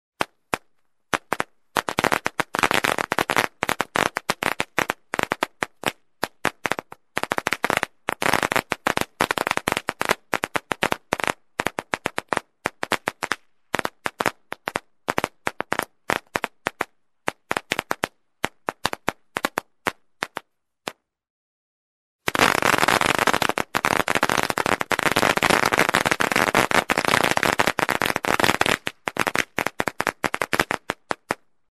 Треск фейерверка